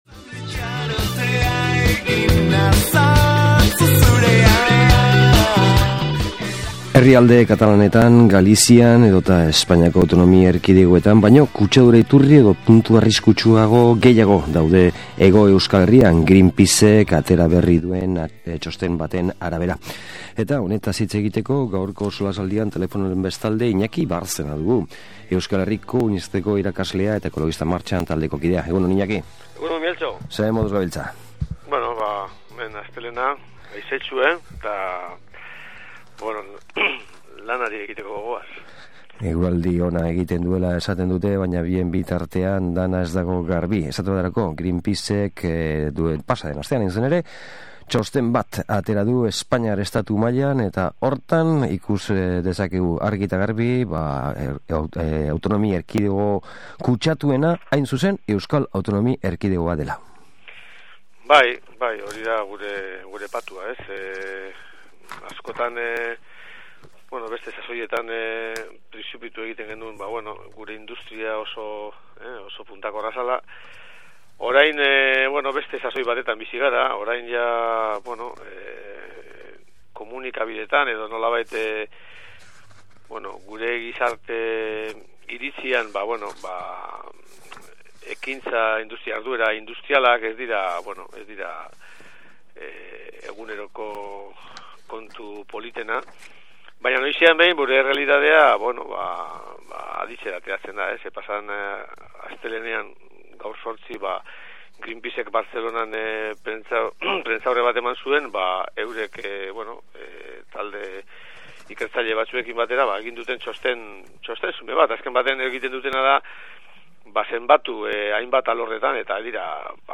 SOLASALDIA: Greenpeaceren txostena